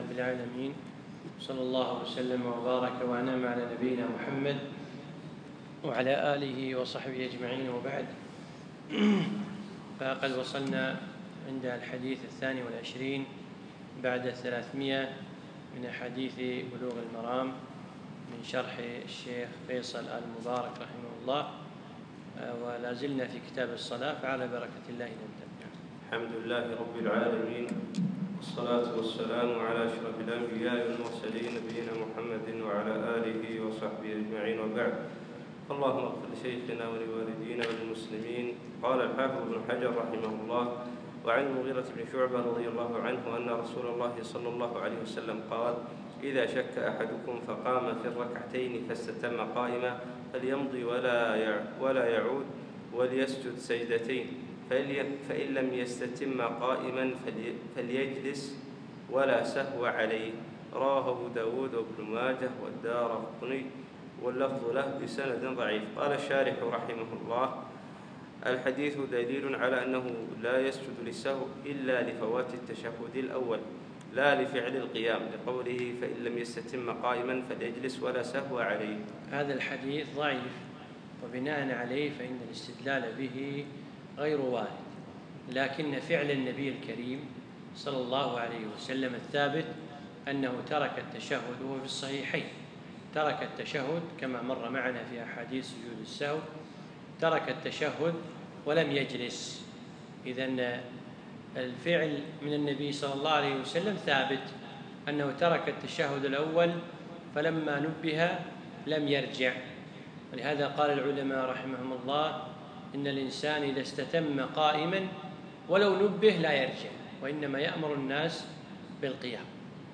يوم الاحد 9 8 2015 في مسجد أحمد العجيل القصور
الدرس الرابع عشر